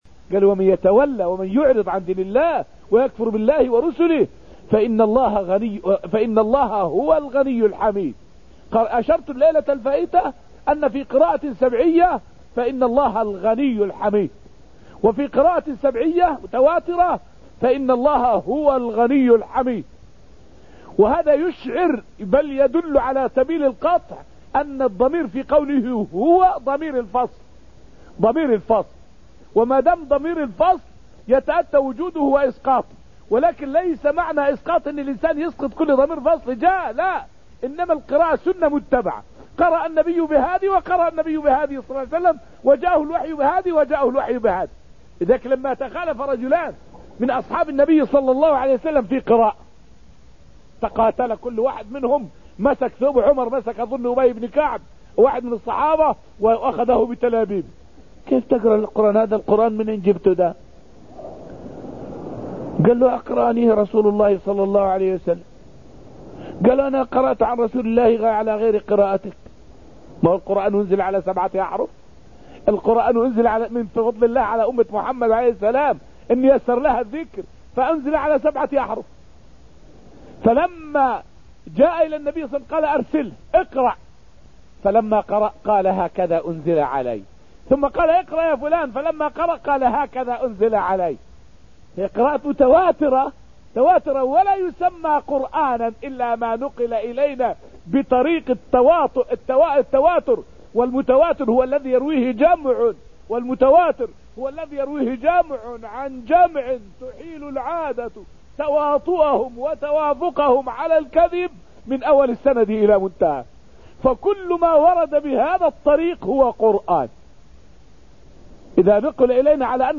فائدة من الدرس الثاني والعشرون من دروس تفسير سورة الحديد والتي ألقيت في المسجد النبوي الشريف حول فوائد حول القراءات القرآنية.